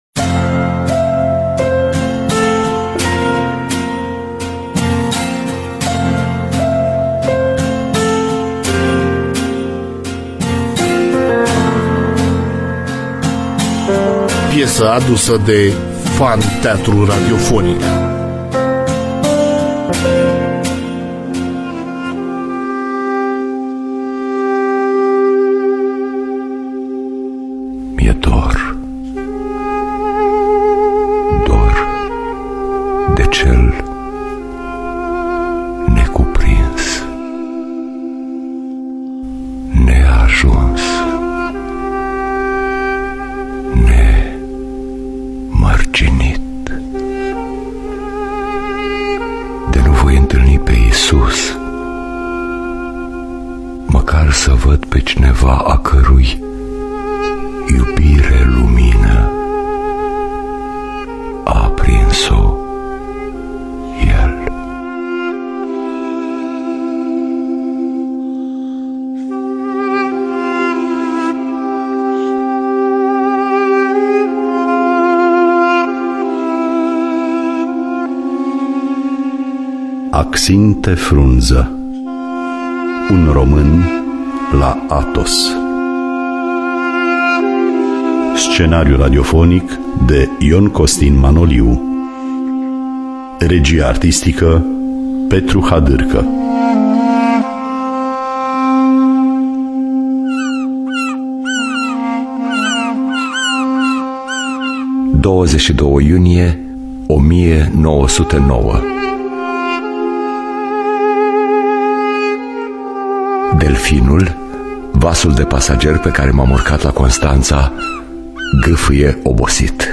Scenariul radiofonic